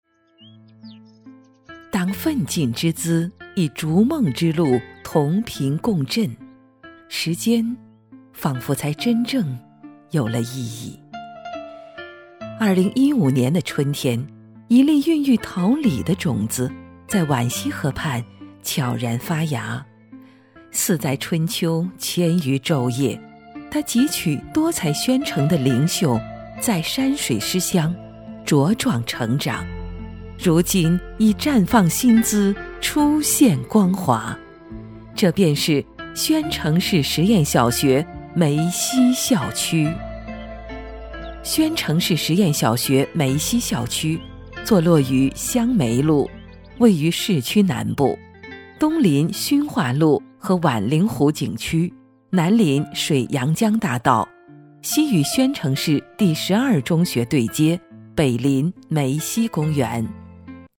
女国187_专题_学校_宣城实验小学.mp3